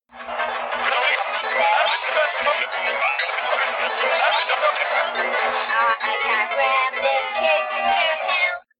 Copyrighted music sample